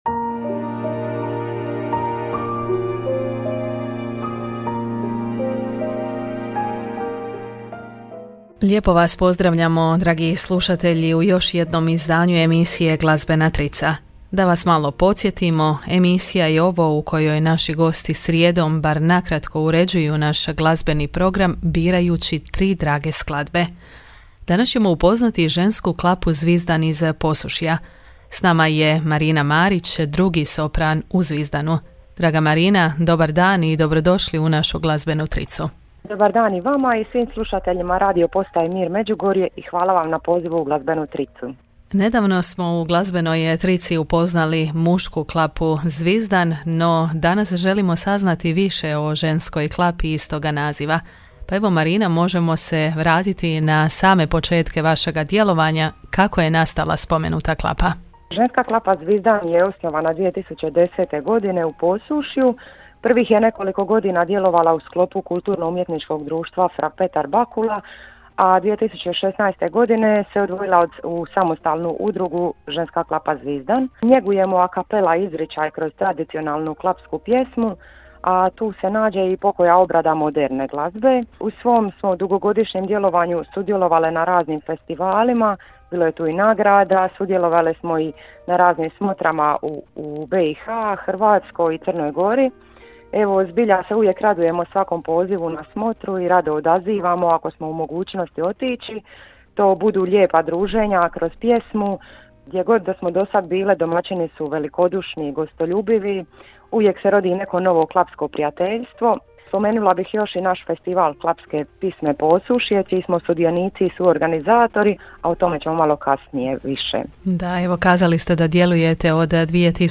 Emisija je ovo u kojoj naši gosti bar na kratko uređuju naš glazbeni program, birajući tri drage skladbe.